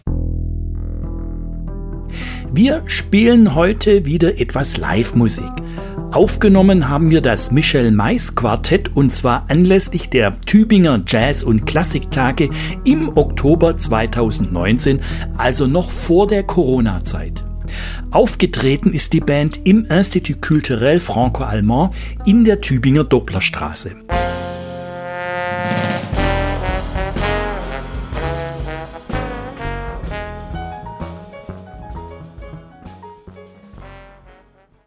Es gibt wieder Live-Musik auf die Ohren.
Heute gibt es wieder Live-Jazz!
Schlagzeug
Piano
Posaune
Bass